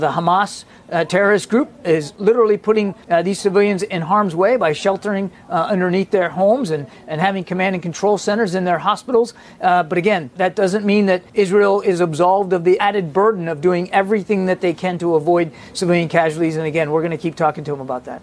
Die woordvoerder van die Nasionale Veiligheidsraad, John Kirby, sê hulle is deur die Israeli’s meegedeel dat daar gedurende die tydperke geen militêre operasies in hierdie gebiede sal plaasvind nie en dat die proses gister reeds begin het.